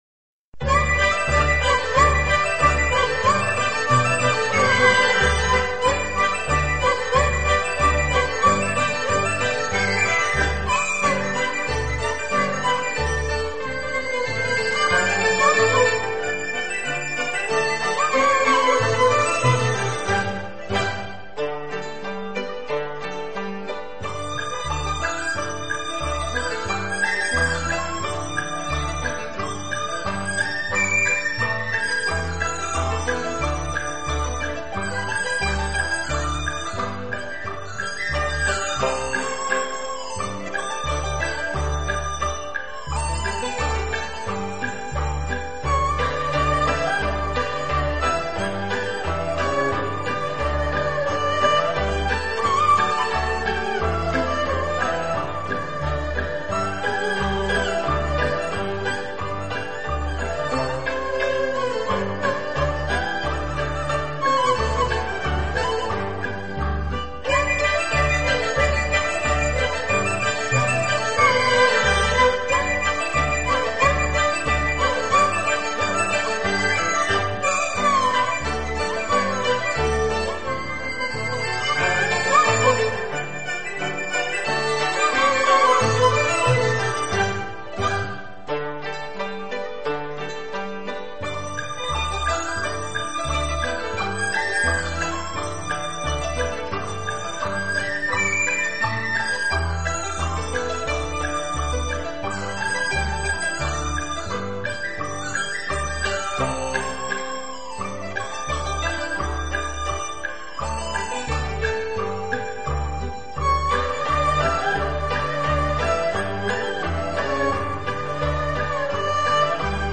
版本：民乐合奏权威
遥远，恬静，玄惑，蕴藏着丰富的暇想。
民乐合奏：通常指弹拨、吹管、拉弦、打击等诸种乐器分照不同声部的联合演奏方式。